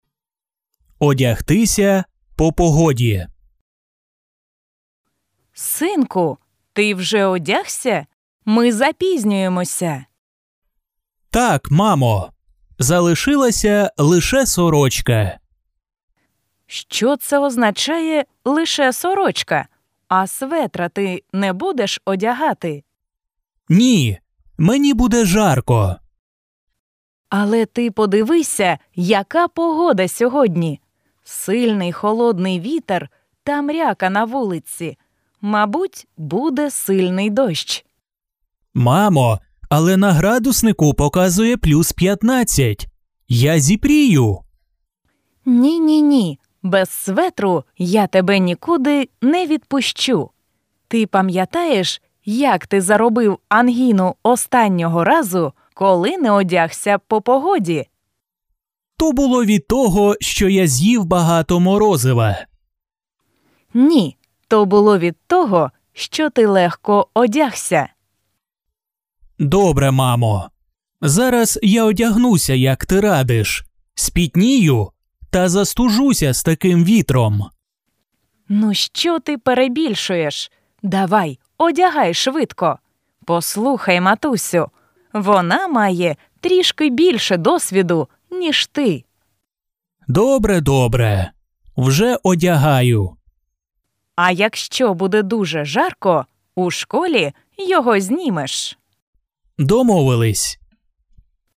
Dialogues with audio